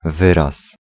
Ääntäminen
US : IPA : [wɝd] UK : IPA : /wɜː(ɹ)d/